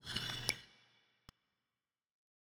sword sheathe.wav